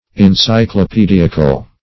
Encyclopediacal \En*cy`clo*pe*di"a*cal\, a.
encyclopediacal.mp3